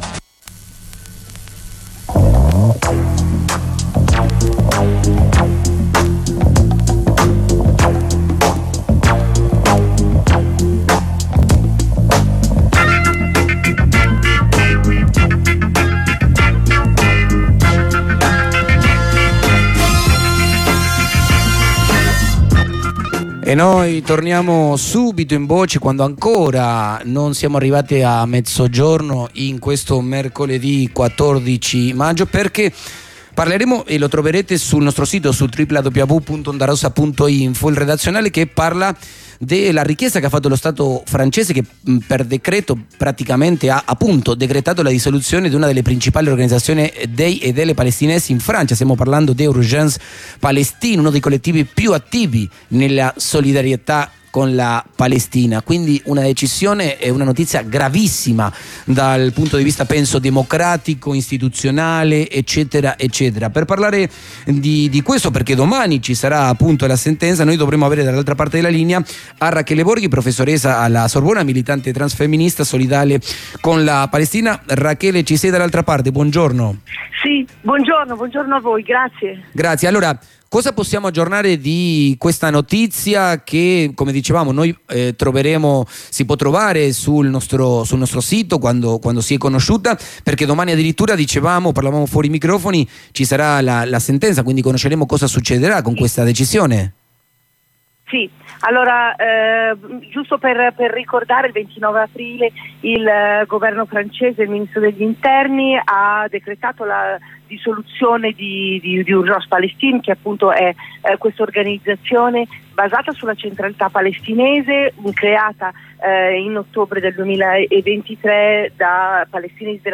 Comunicazione telefonica